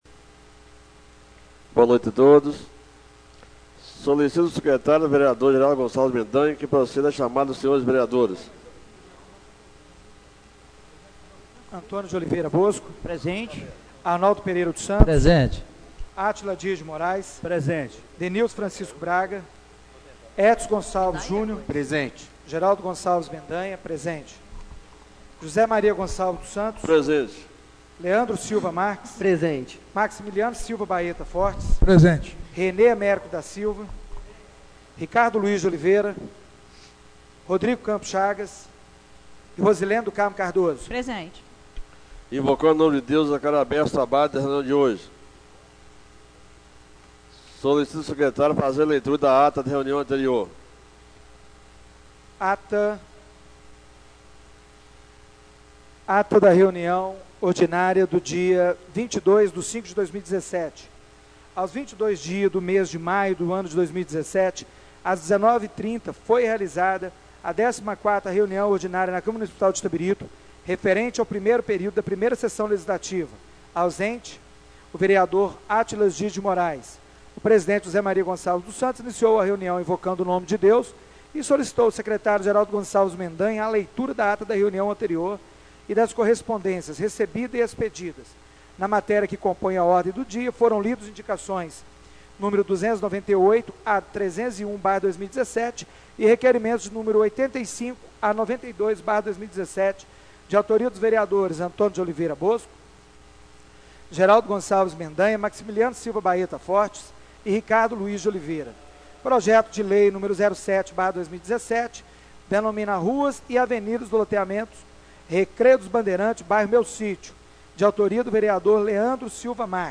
Reunião Ordinária do dia 29/05/2017